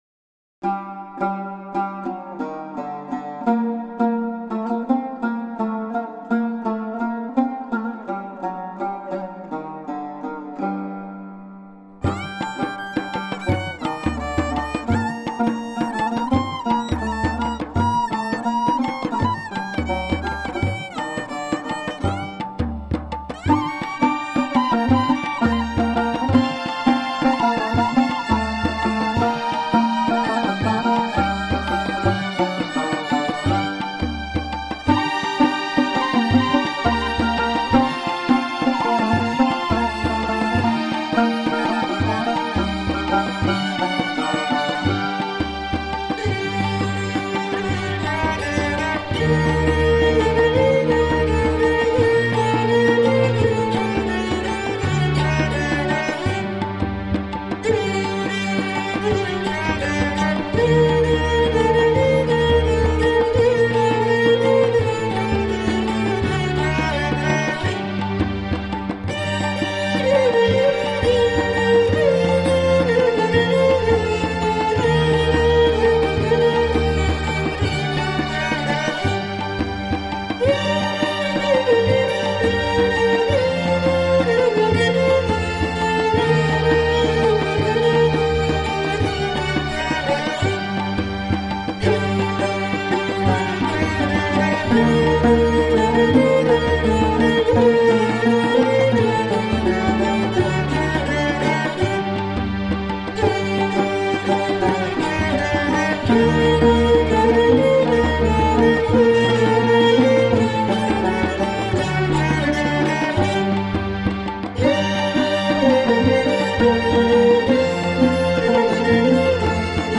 Tagged as: New Age, Ambient, World